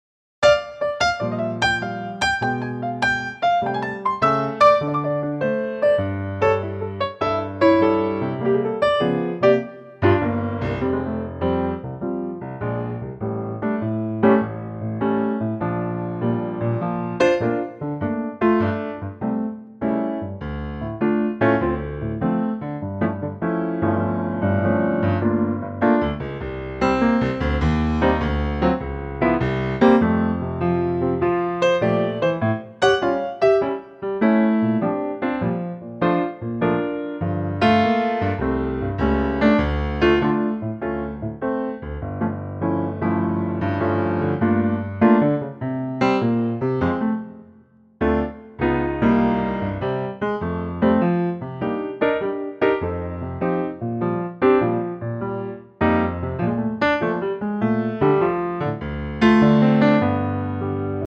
key - F - vocal range - F to A